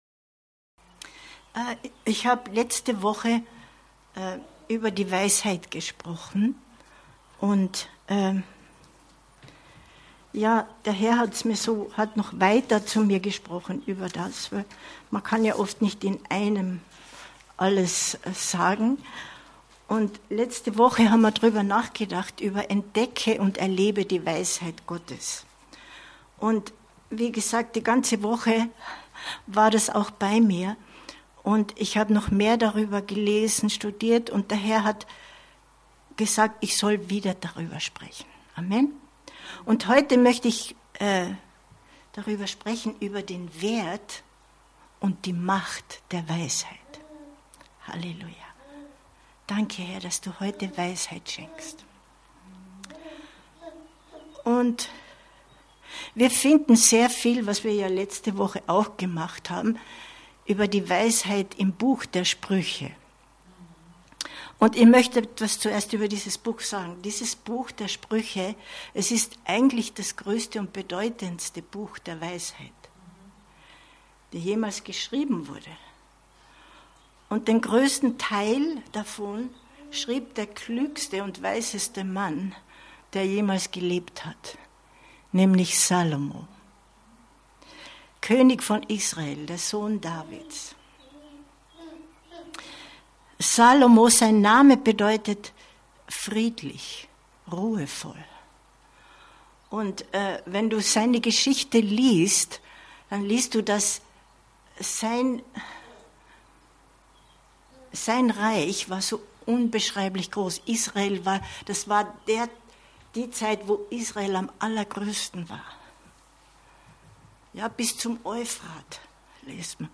Der Wert und die Macht der Weisheit 25.06.2023 Predigt herunterladen